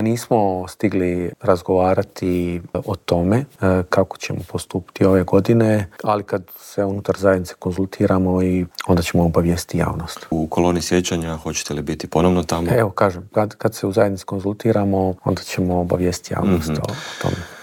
Predsjednik Srpskog narodnog vijeća Boris Milošević u Intervjuu tjedna Media servisa poručuje da je teško objasniti kako je iz jedne male kulturne priredbe ovo preraslo u tako veliki problem.